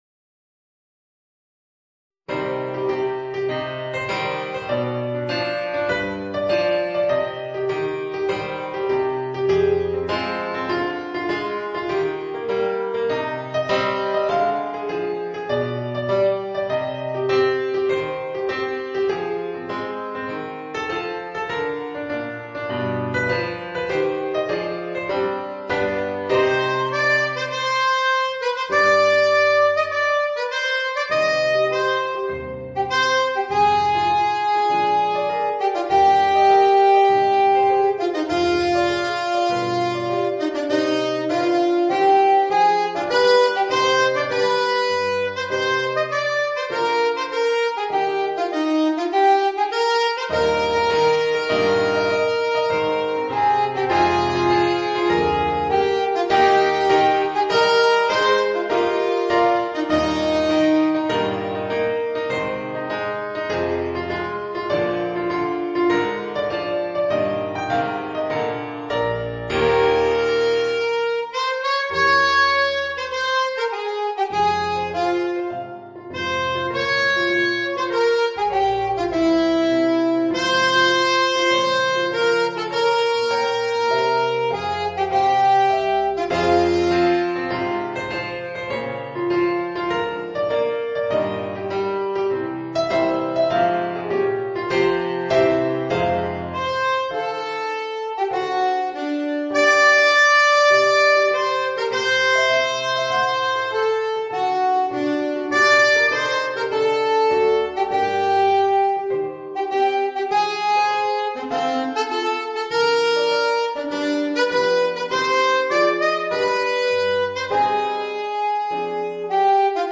Voicing: Alto Saxophone and Organ